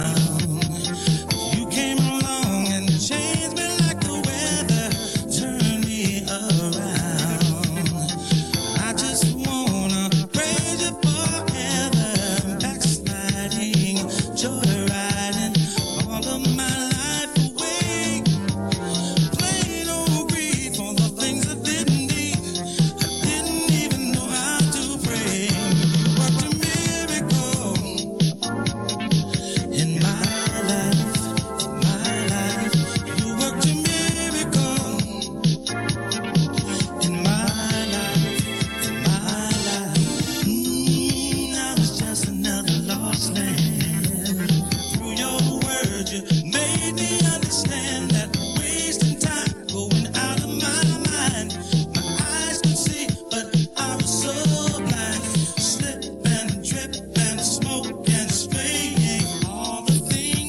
Gospel / Boogie